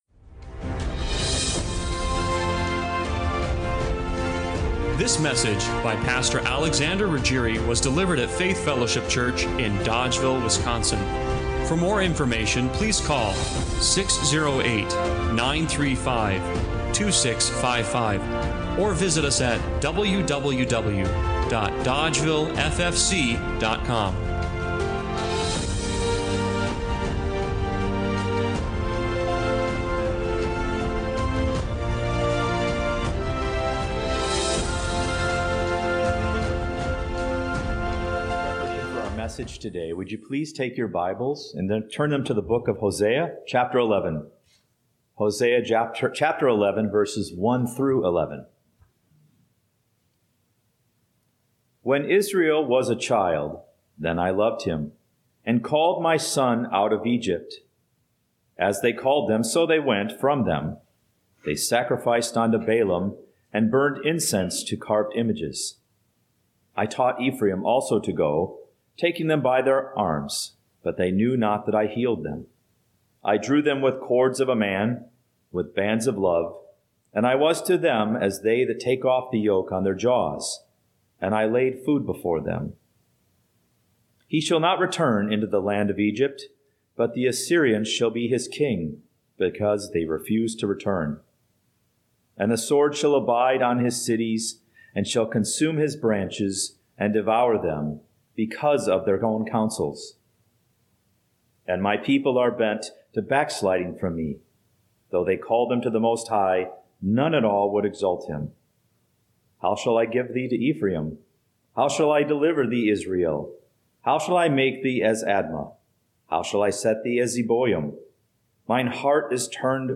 2024 Bent on Backsliding Preacher